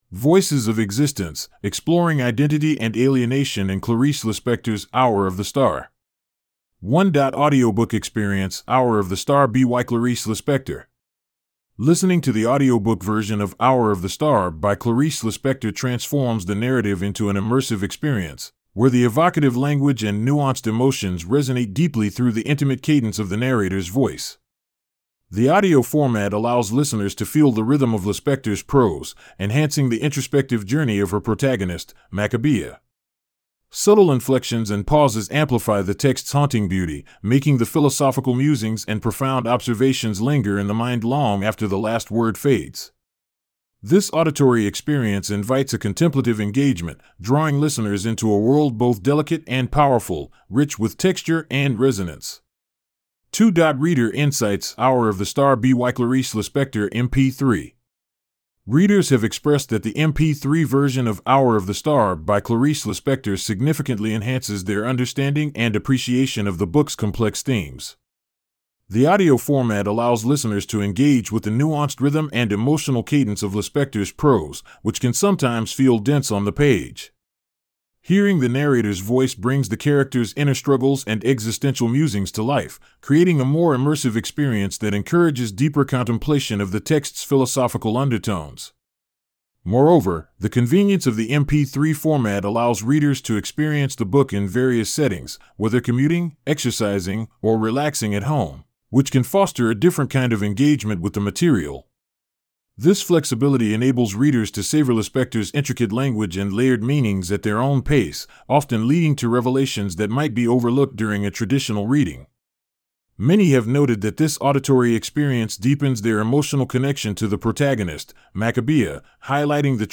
1.Audiobook Experience:Hour Of The Star BYClarice Lispector Listening to the audiobook version of "Hour of the Star" by Clarice Lispector transforms the narrative into an immersive experience, where the evocative language and nuanced emotions resonate deeply through the intimate cadence of the narrator's voice.